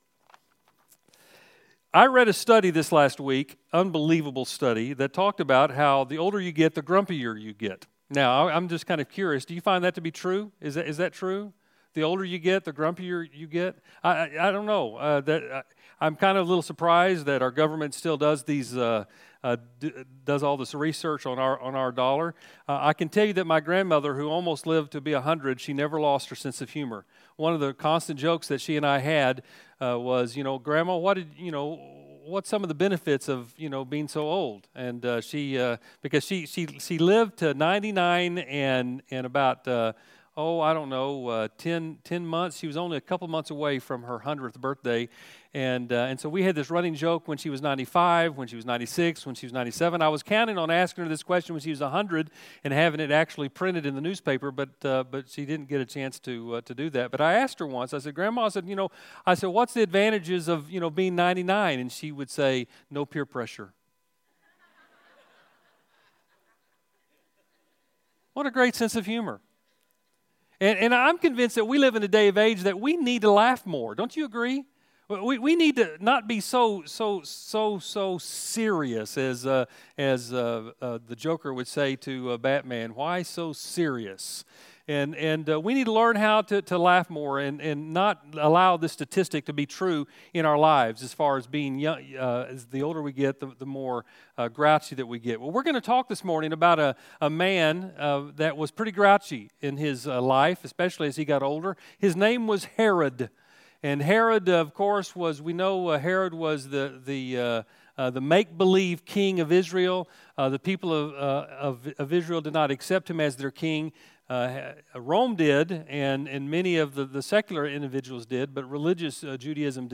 A message from the series "Star Wars."